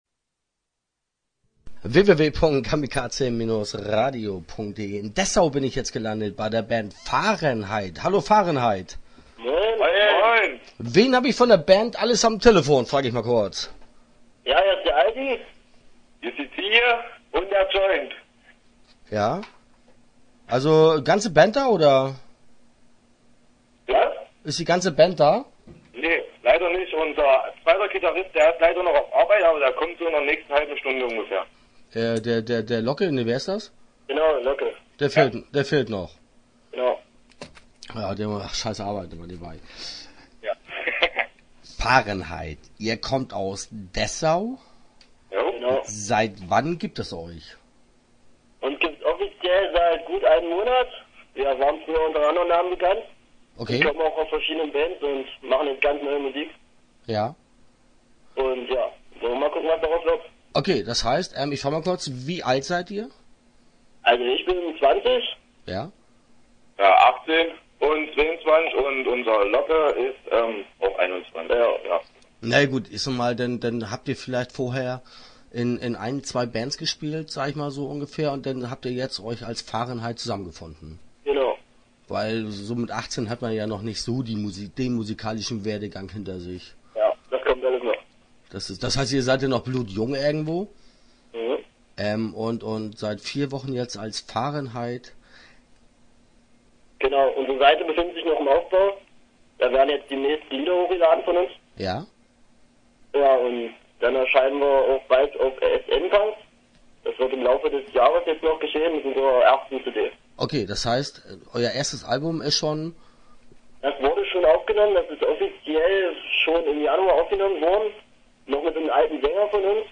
Start » Interviews » Fahrenheit